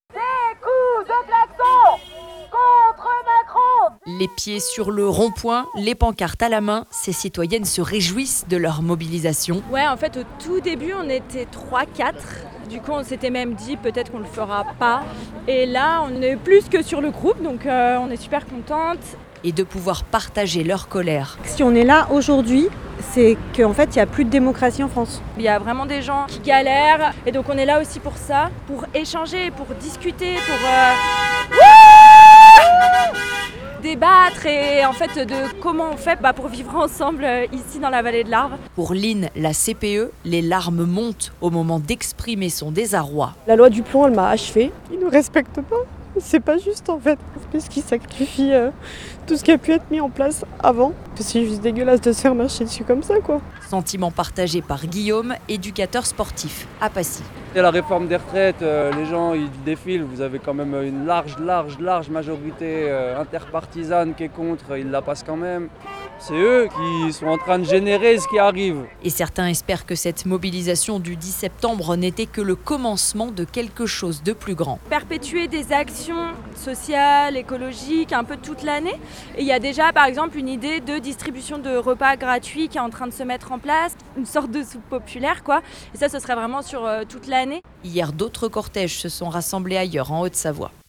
Une quarantaine de personnes étaient rassemblées mercredi 10 septembre au rond-point de l’Europe.